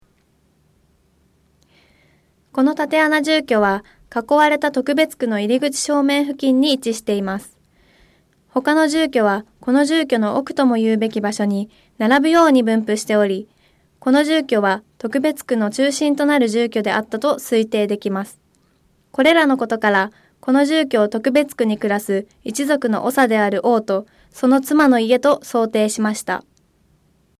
音声ガイド 前のページ 次のページ ケータイガイドトップへ (C)YOSHINOGARIHISTORICAL PARK